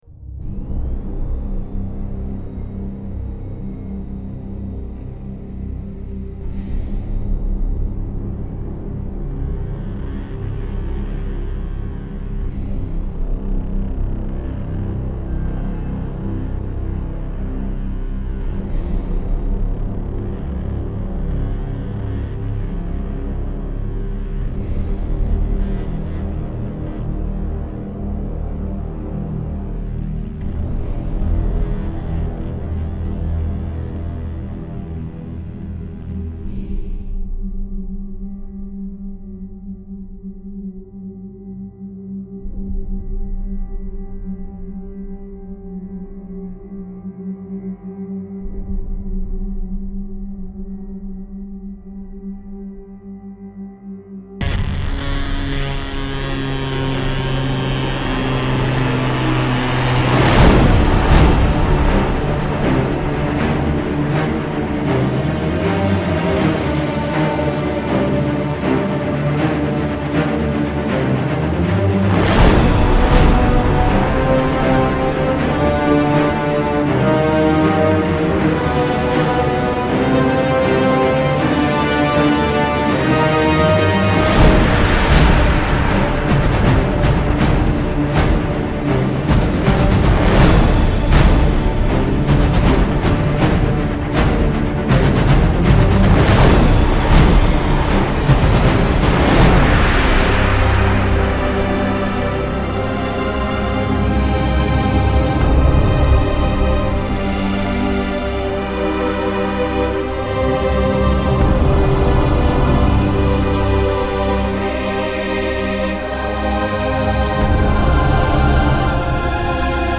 Étouffante et rageuse comme le film, ok, mais laborieuse.